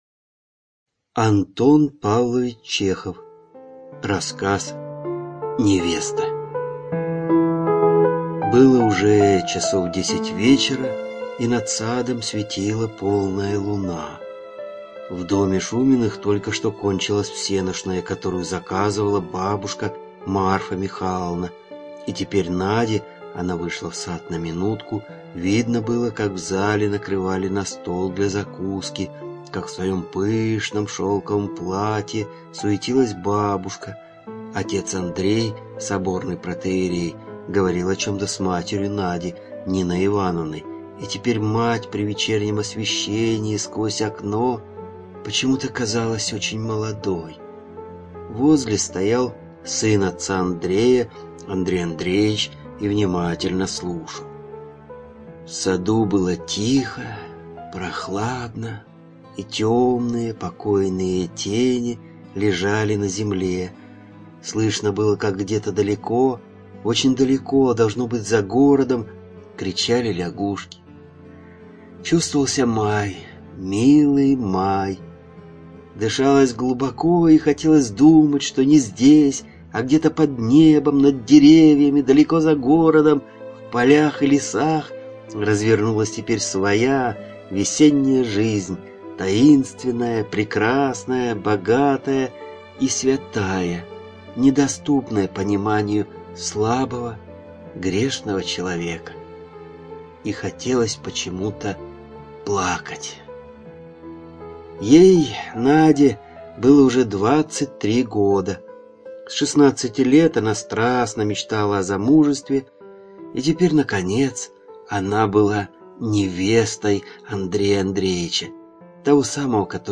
ЖанрКлассическая проза